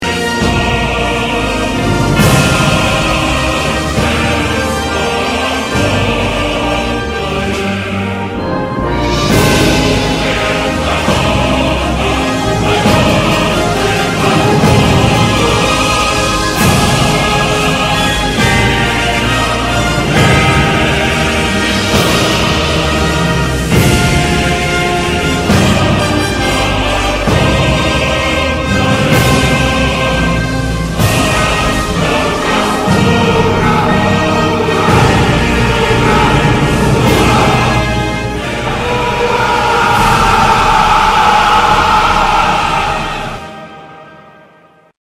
Soviet Anthem Whit Urraaaa Sound Button | Sound Effect Pro